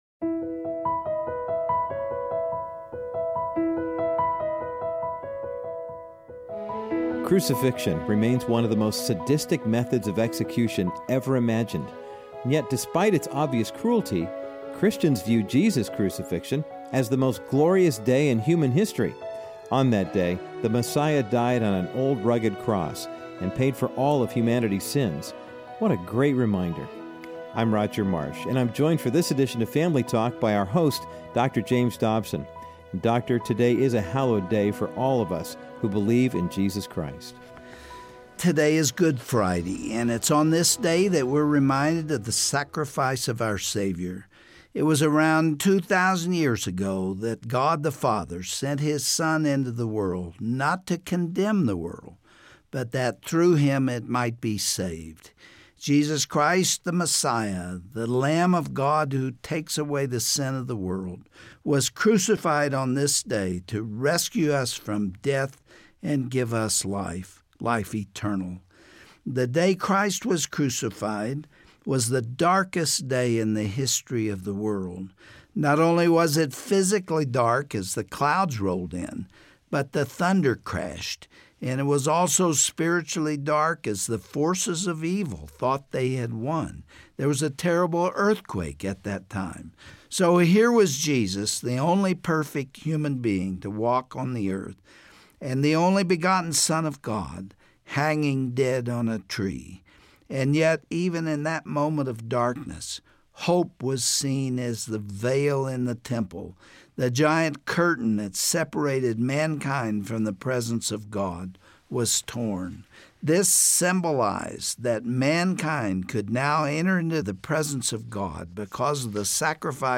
Crucifixion may be the most horrific and sadistic form of capital punishment ever invented by the human mind. On this 'Good Friday' edition of Family Talk, you will hear a powerful article which describes the physical torture and death Jesus experienced from a medical perspective.